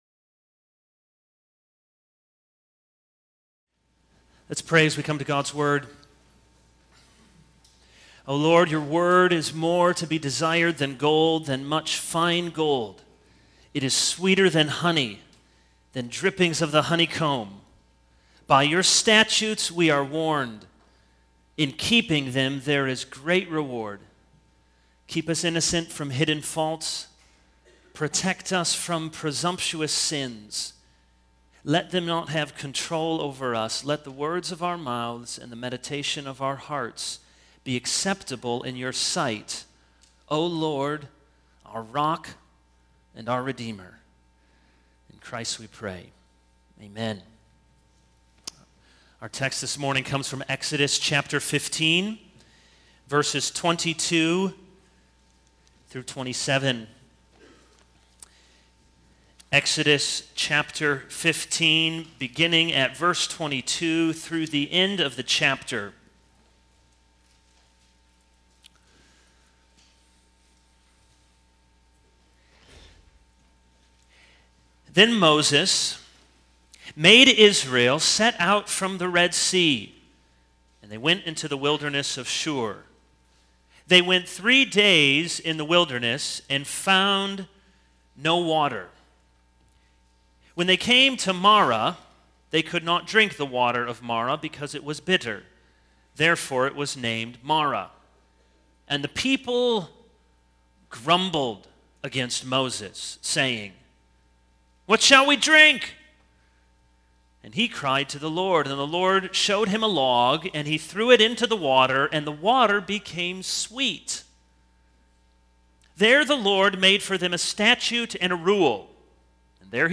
Sermon Summary / Transcript